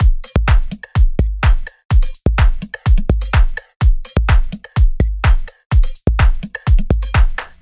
硬屋环路
描述：一个用多种hihat效果和其他东西组成的短循环...请欣赏
标签： 126 bpm House Loops Drum Loops 1.28 MB wav Key : Unknown
声道立体声